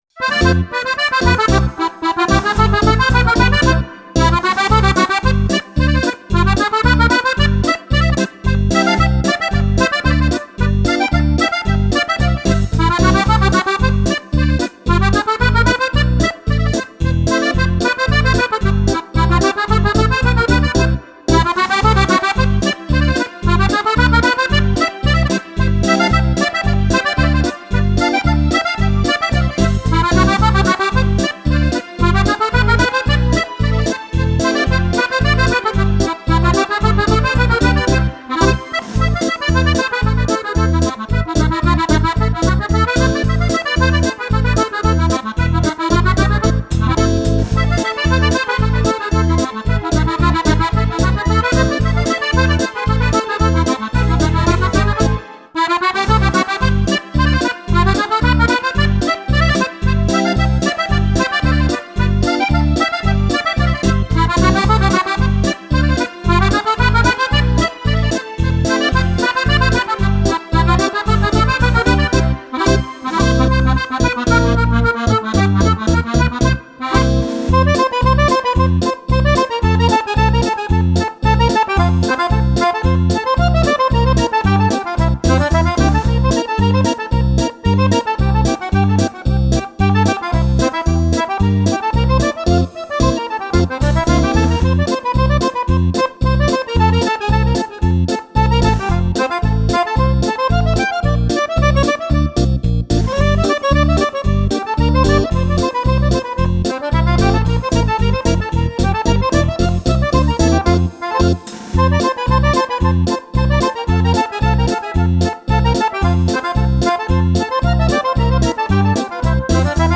Polca
ballabili per fisarmonica